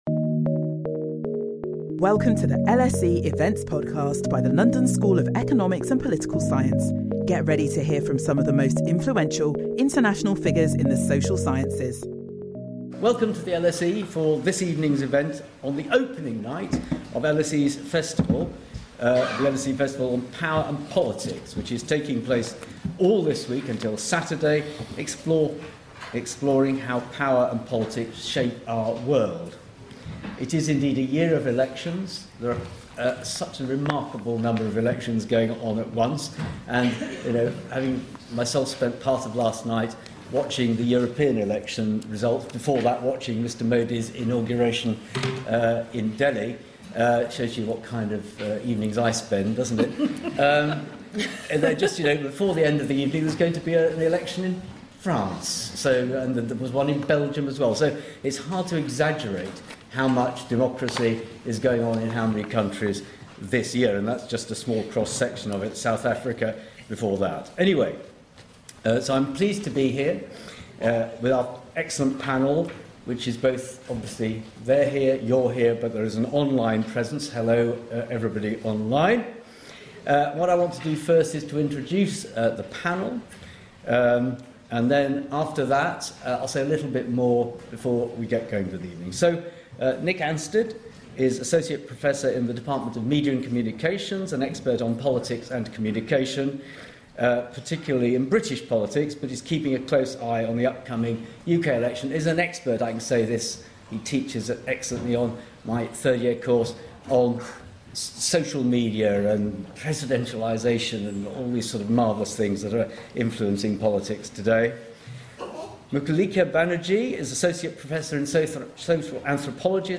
Our panel explore some of the issues coming to the fore in this bumper year for politics as well as their implications.